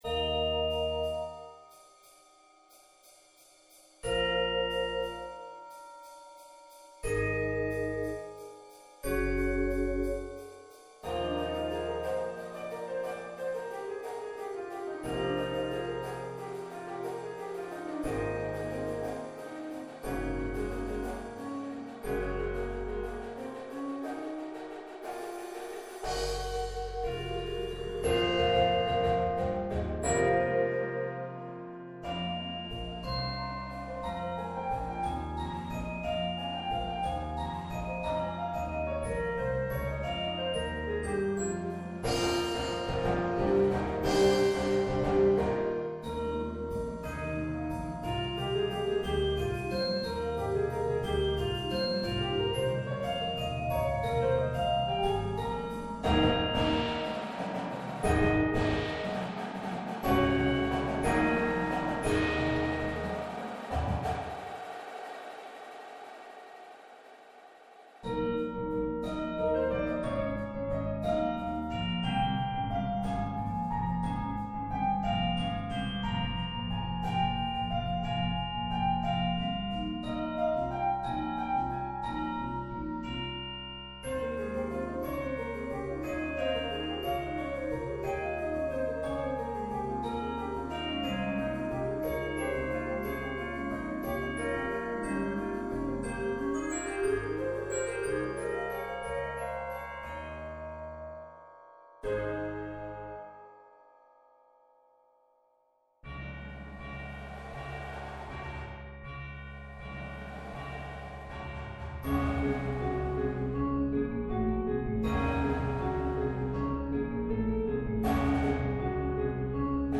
Bells
Marimba
Vibes (2)
Chimes
Synth
Electric Bass
Drumset
Auxiliary Percussion
Snare
Tenors (quints)
Bass Drums (4 )